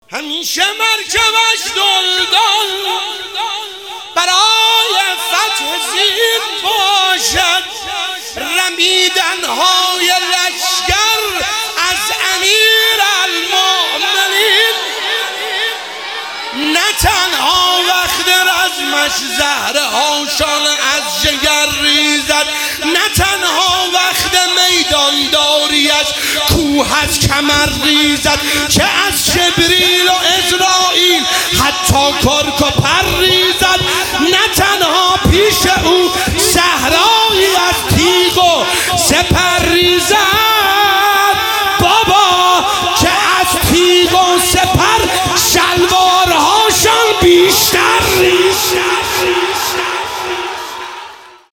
میلاد حضرت زهرا سلام الله علیها 1400 | هیئت فاطمه الزهرا قم